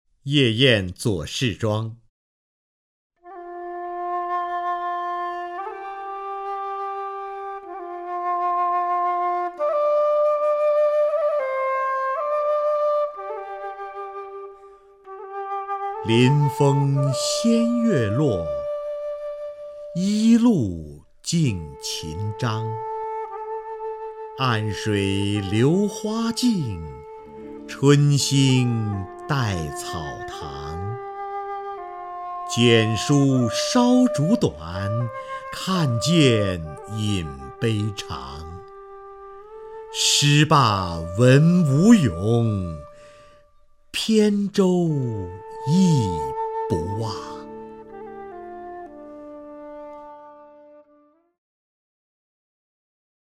瞿弦和朗诵：《夜宴左氏庄》(（唐）杜甫)
名家朗诵欣赏 瞿弦和 目录